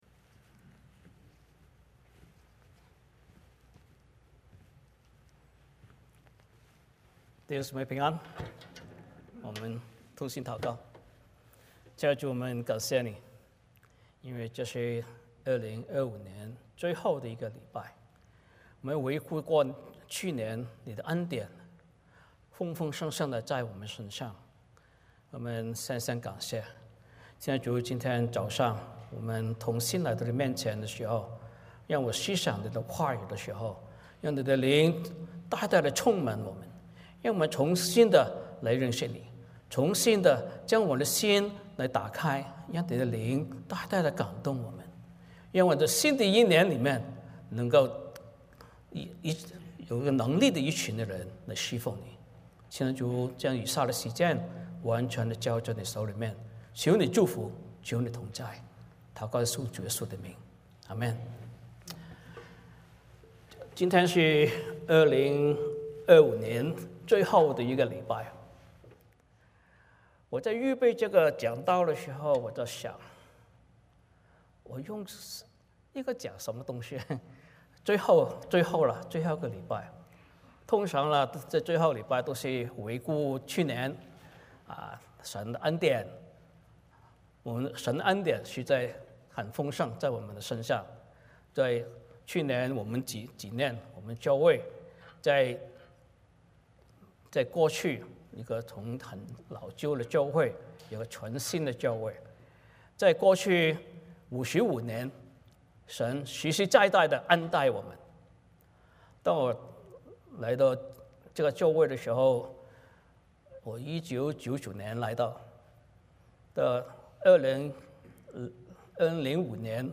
使徒行传 11:1-18 Service Type: 主日崇拜 欢迎大家加入我们的敬拜。